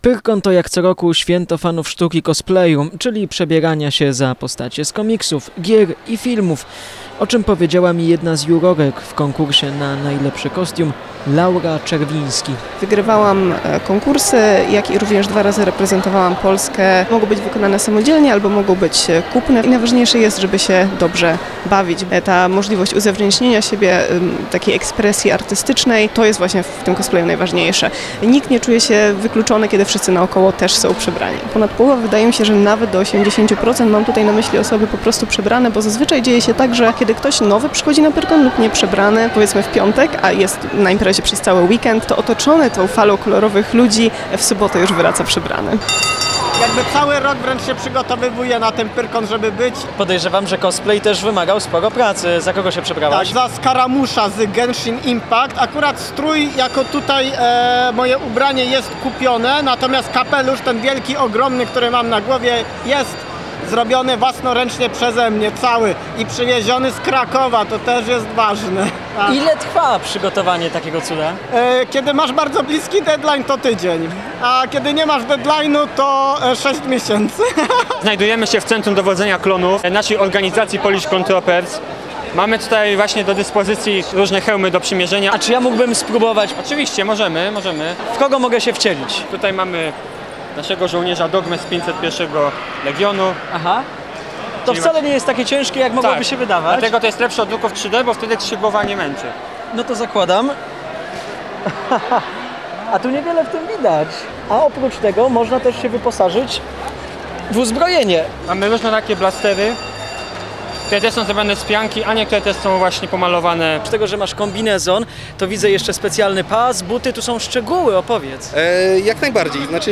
1iroz57cd78bcnu_material_pyrkon-cosplay.mp3